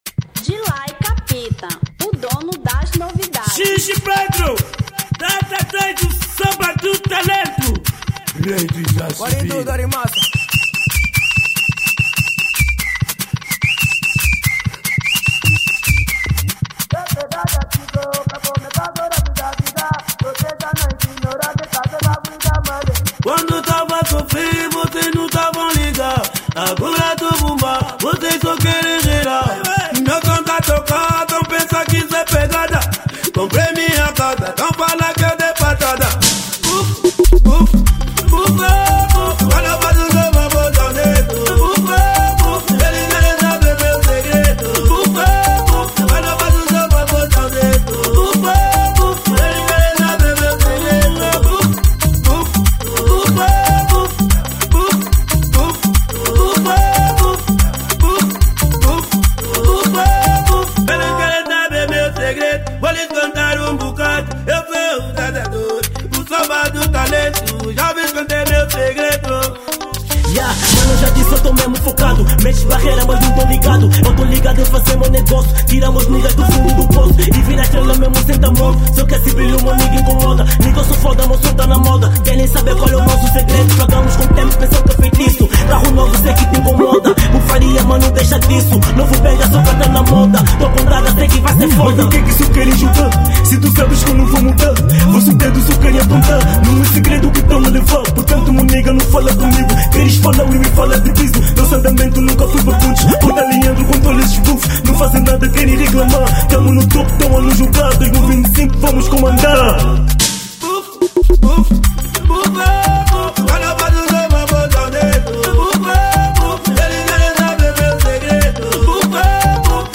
Kuduro